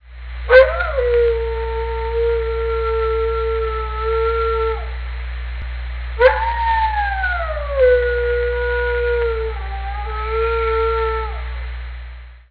Click HERE to hear Rascal call the neighborhood children when they get off of the school bus.